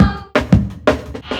JUNGLE2-R.wav